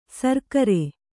♪ sarkare